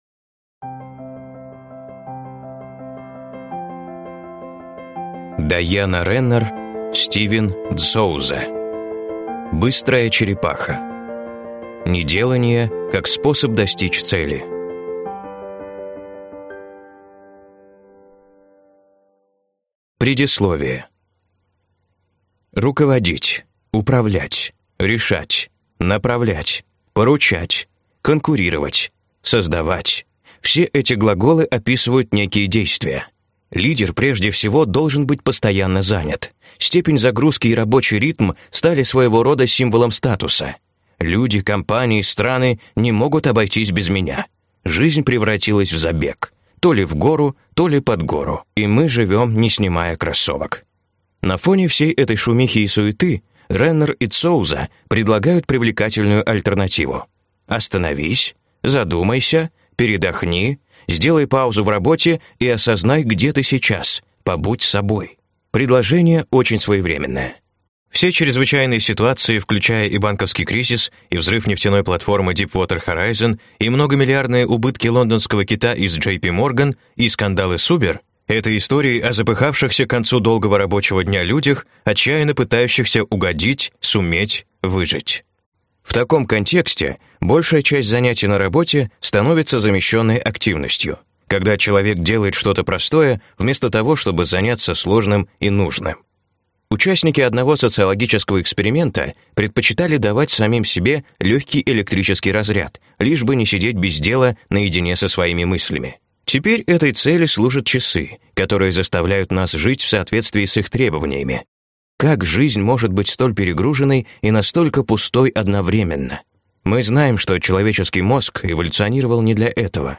Аудиокнига Быстрая черепаха | Библиотека аудиокниг